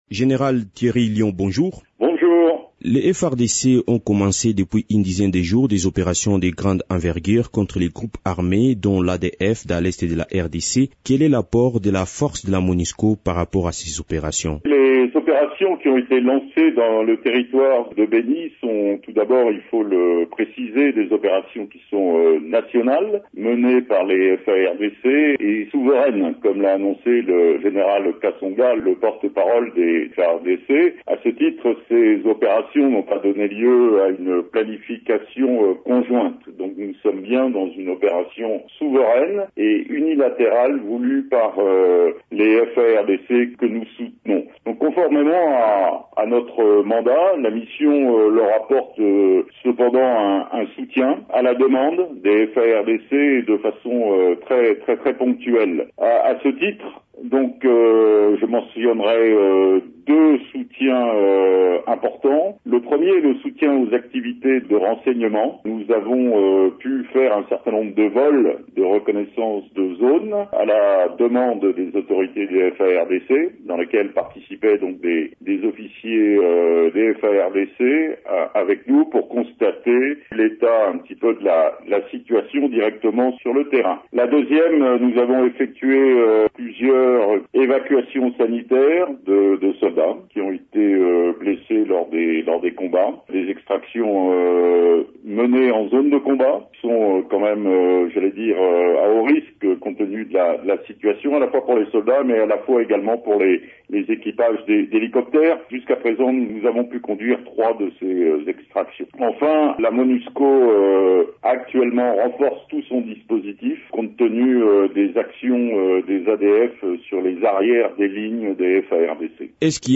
09.invite_beni_commandant_des_forces_de_la_monusco.mp3